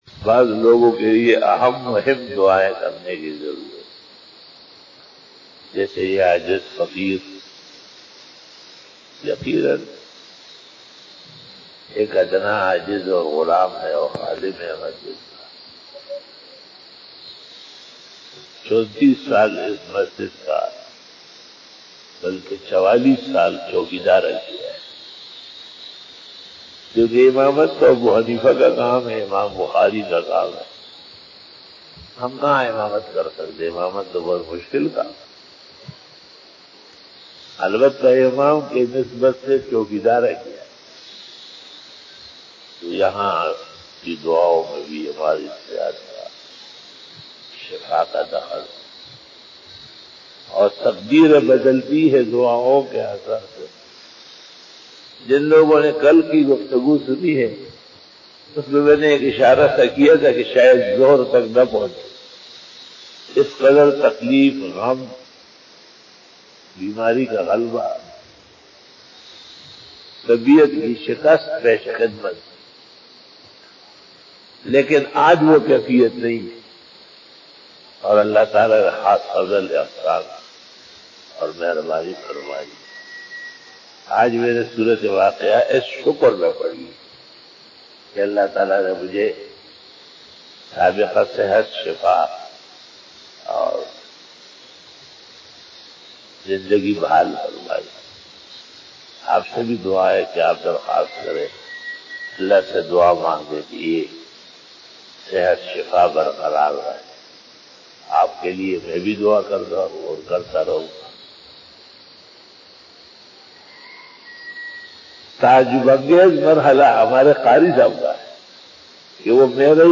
بعد نماز فجر بیان 17 ستمبر 2020ء بمطابق 28 محرم الحرام 1442ھ بروزجمعرات